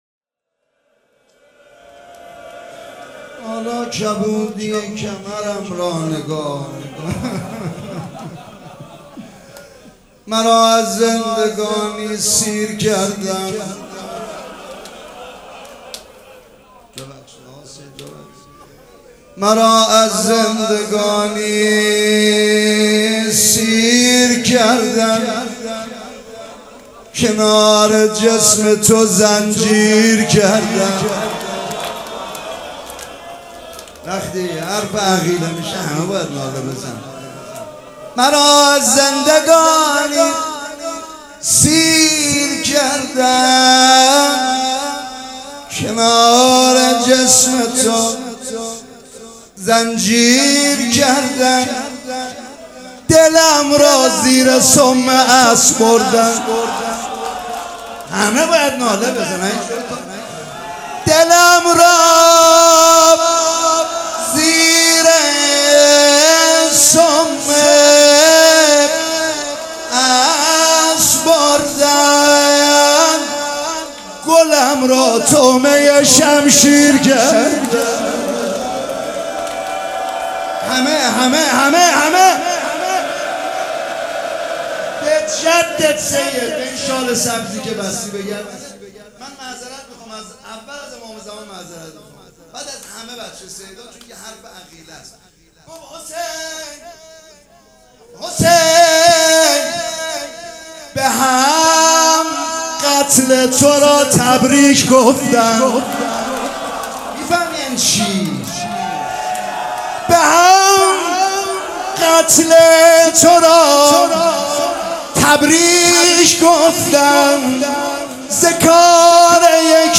روضه
مراسم عزاداری شب دوم